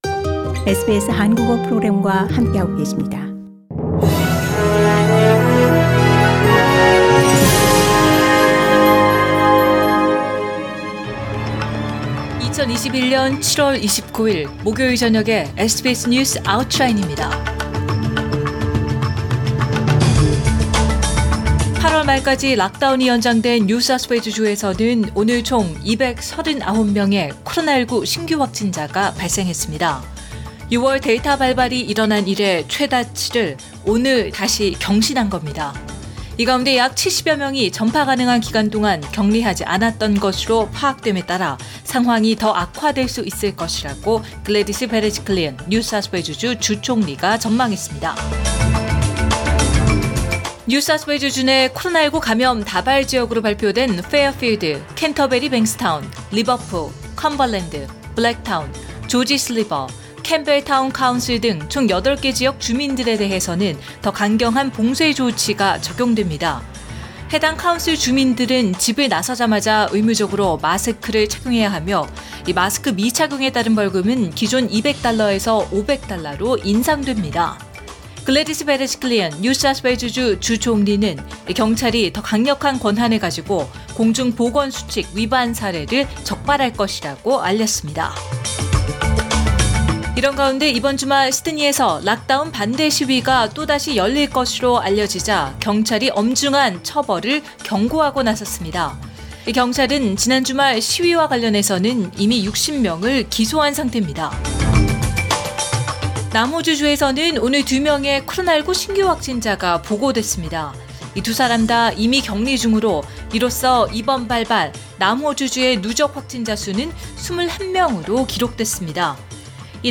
2021년 7월 29일 목요일 저녁의 SBS 뉴스 아우트라인입니다.